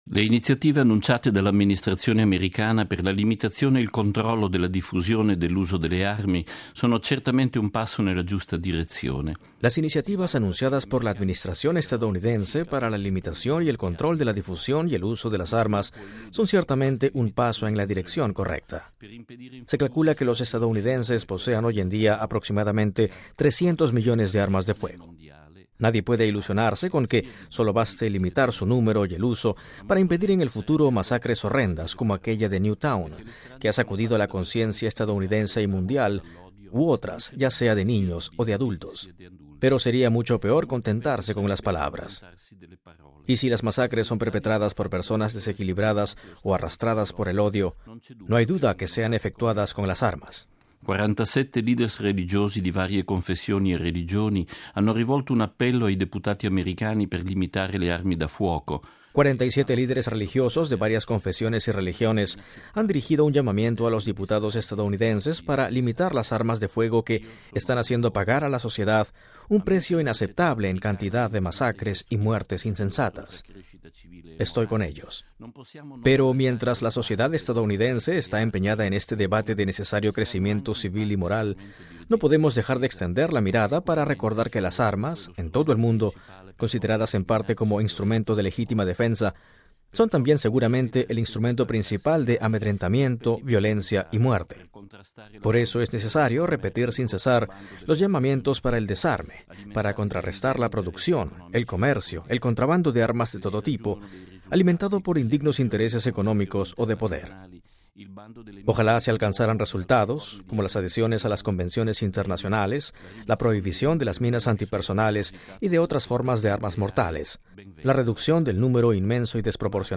Editorial semanal de nuestro director general, p. Federico Lombardi